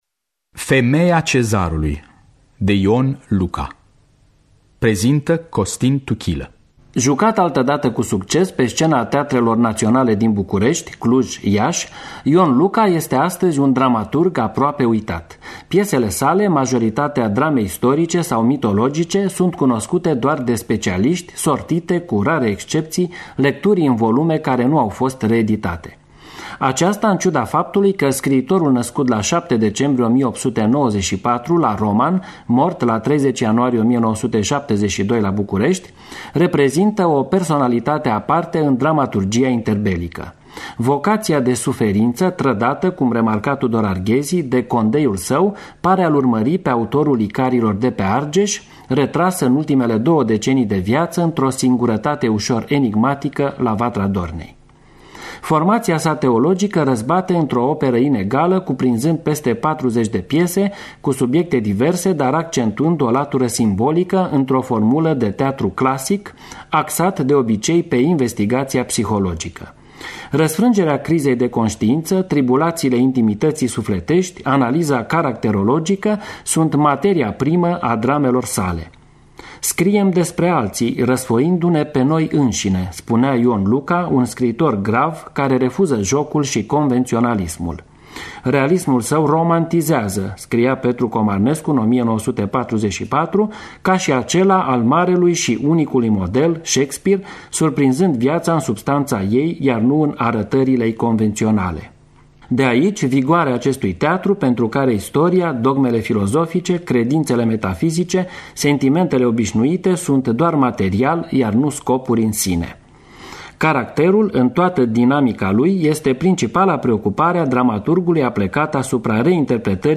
Femeia Cezarului de Ion Luca – Teatru Radiofonic Online